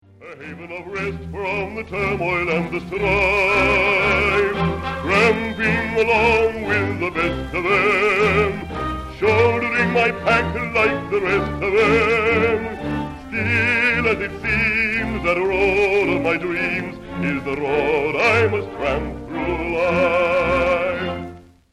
Bass singer with orchestra